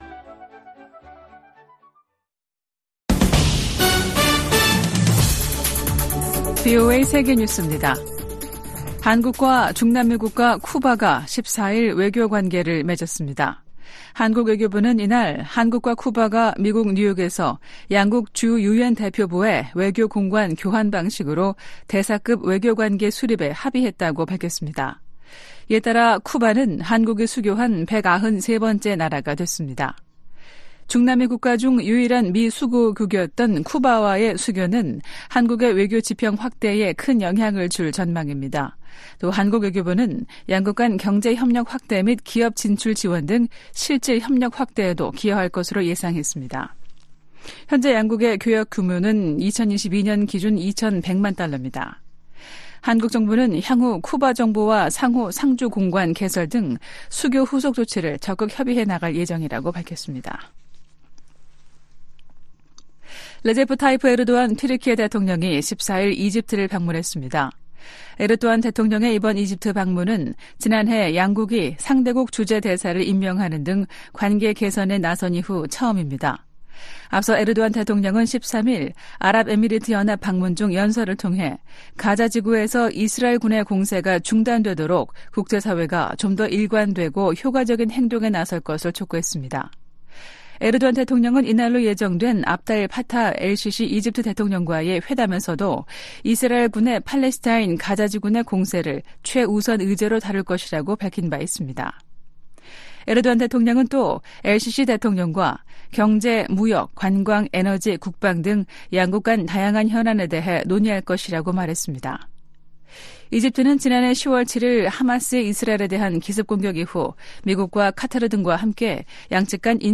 VOA 한국어 아침 뉴스 프로그램 '워싱턴 뉴스 광장' 2024년 2월 15일 방송입니다. 북한이 또 동해상으로 순항미사일을 여러 발 발사했습니다. 미 국무부는 북한이 정치적 결단만 있으면 언제든 7차 핵실험을 감행할 가능성이 있는 것으로 판단하고 있다고 밝혔습니다. 백악관이 북한의 지속적인 첨단 무기 개발 노력의 심각성을 지적하며 동맹 관계의 중요성을 강조했습니다.